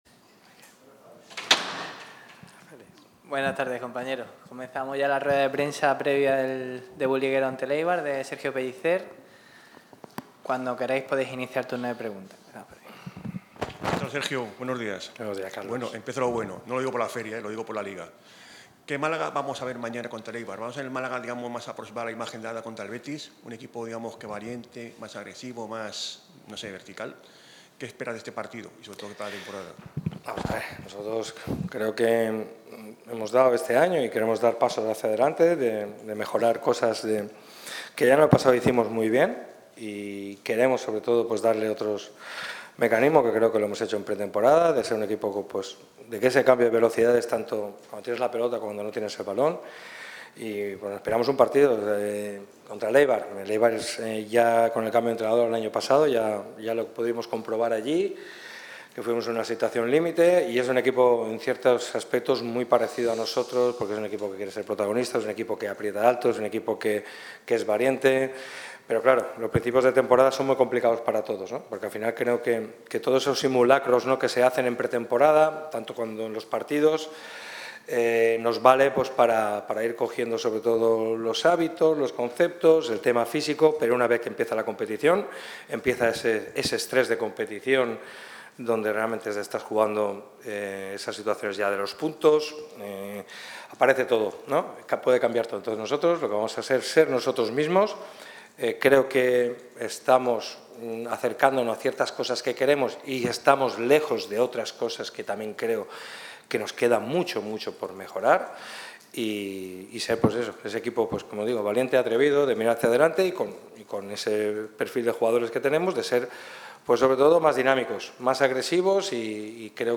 Rueda de prensa previa al partido, el partido y la rueda de prensa postpartido.